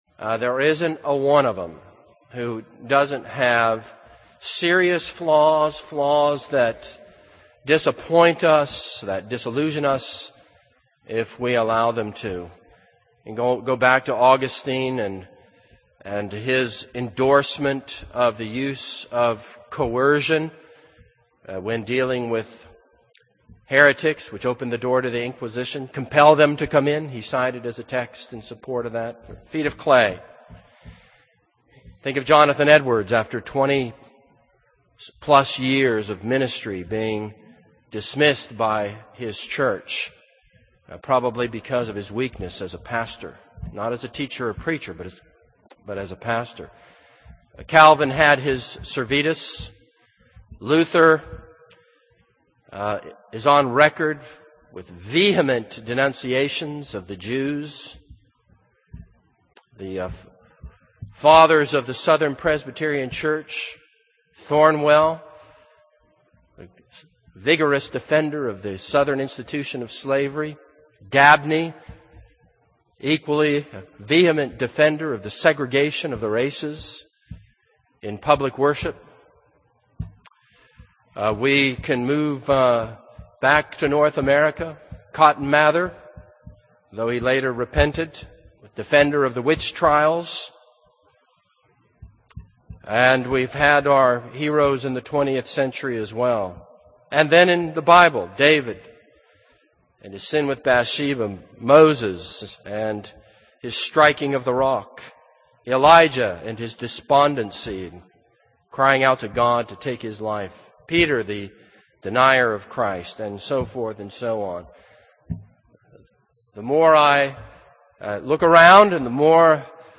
This is a sermon on 2 Kings 18:9-37.